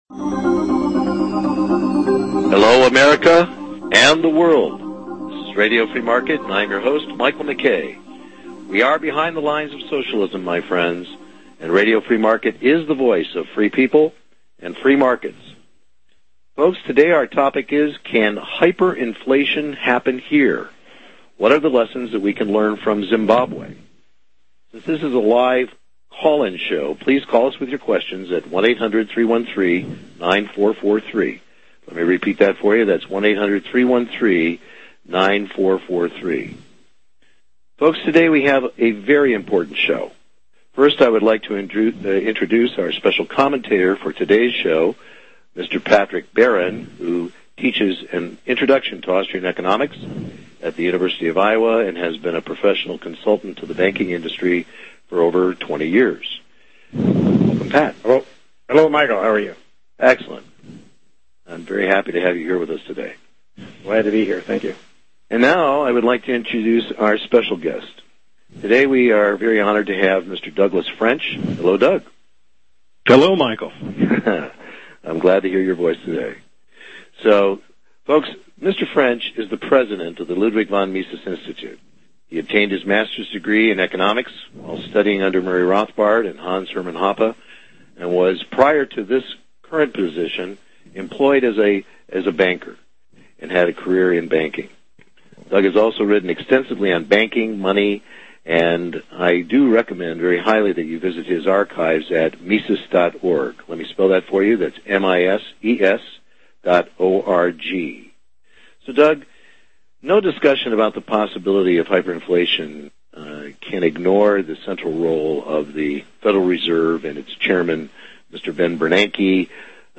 Zimbabwe currently has the most severe Hyper-Inflation in the world yet most people know very little about the details. Learn in this interview the dangerous similarities between the strategies of Zimbabwe’s Central Bank that brought about their turmoil and the misguided strategies of our very own ‘ Helicopter ‘ Ben Bernanke .